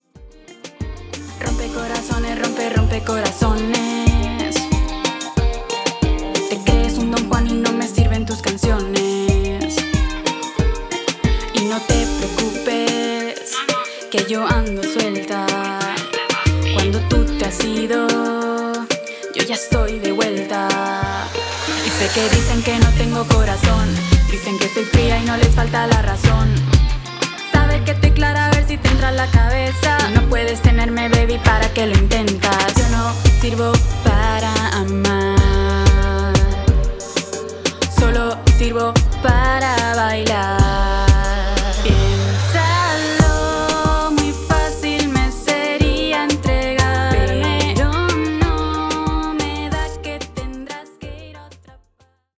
REGGAETON POP